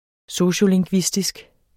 Udtale [ ˈsoɕo- ]